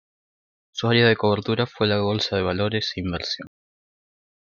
co‧ber‧tu‧ra
/kobeɾˈtuɾa/